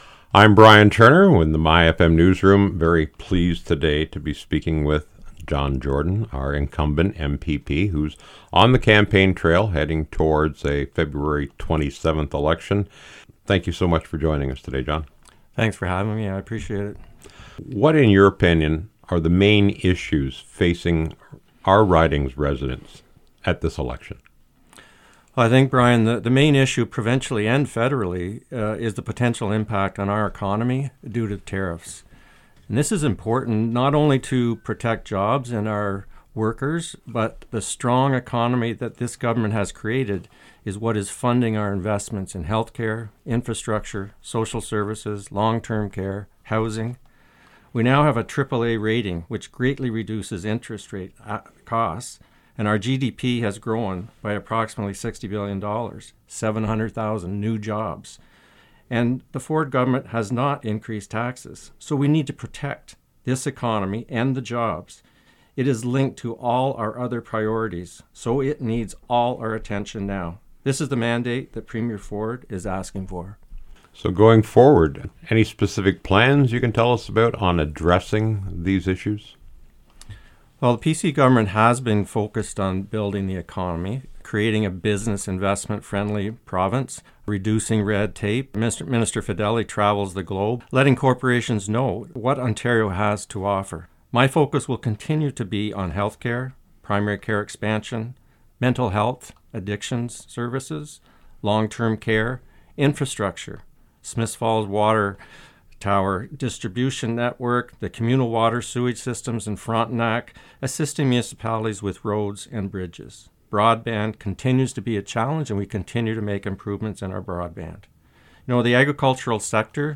myFM news sat down with Lanark Frontenac Kingston incumbent MPP John Jordan of the Progressive Conservatives to get his views on the upcoming election and what’s concerning area voters. At the top of his list was the threat of a trade war between Canada and the US.